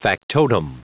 Prononciation du mot factotum en anglais (fichier audio)
Prononciation du mot : factotum